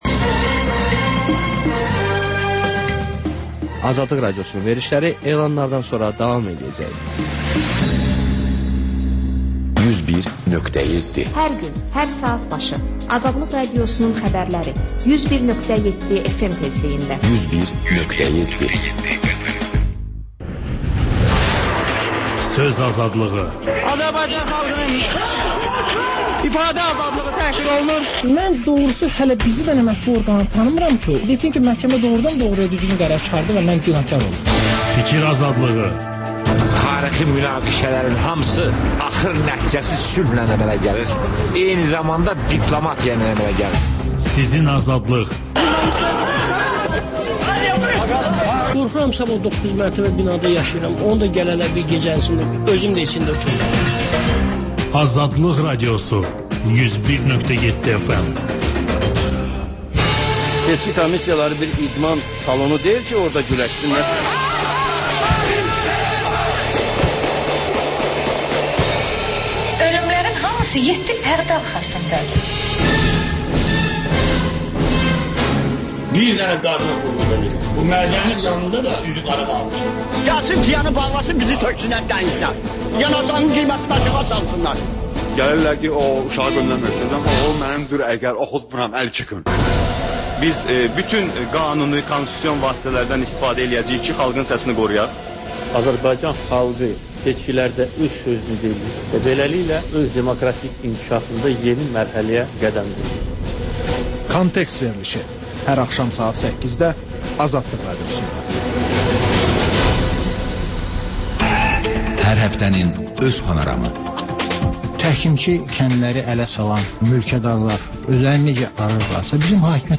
Xəbərlər, HƏMYERLİ: Xaricdə yaşayan azərbaycanlılar haqda veriliş, sonda MÜXBİR SAATI: Müxbirlərimizin həftə ərzində hazırladıqları ən yaxşı reportajlardan ibarət paket